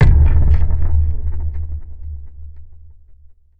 Synth Impact 02.wav